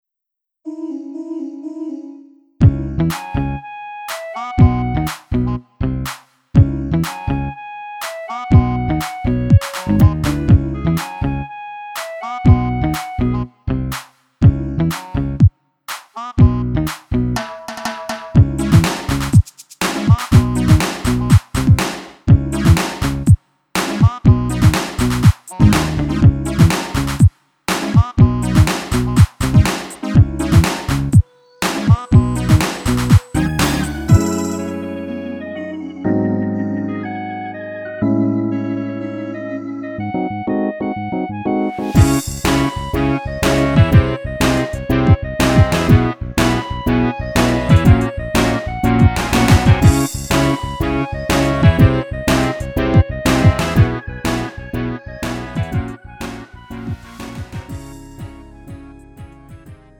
음정 -1키 장르 가요